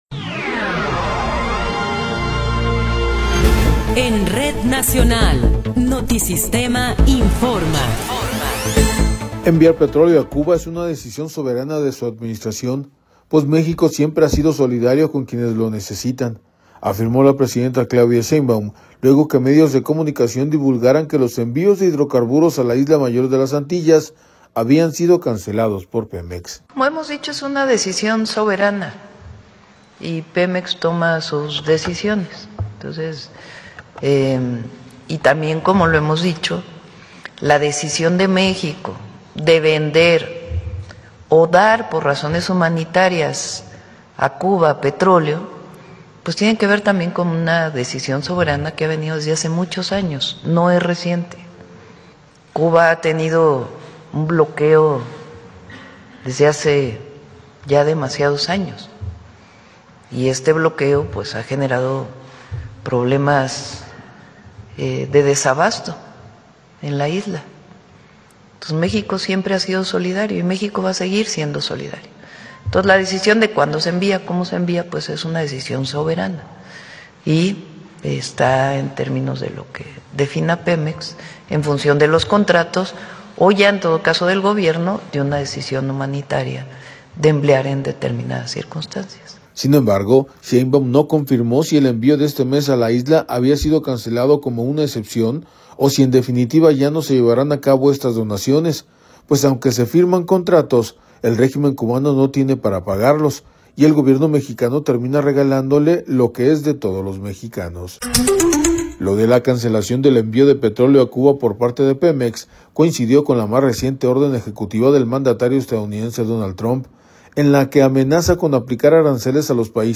Noticiero 18 hrs. – 1 de Febrero de 2026